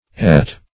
At - definition of At - synonyms, pronunciation, spelling from Free Dictionary